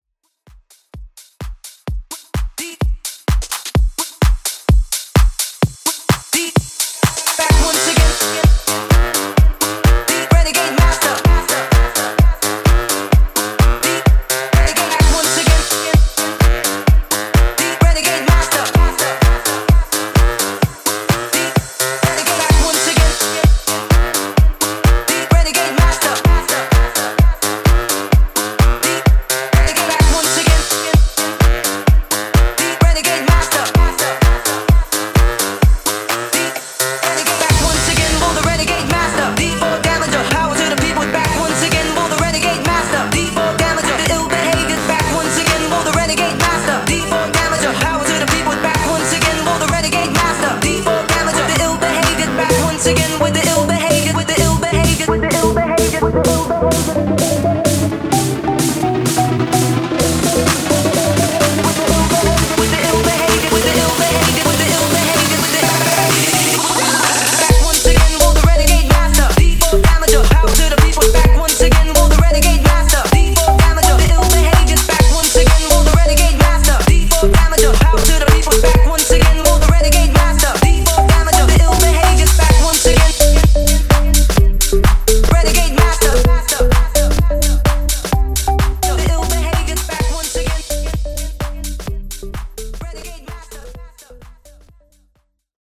MASHUPS , OLD SCHOOL HIPHOP Version: Dirty BPM: 90 Time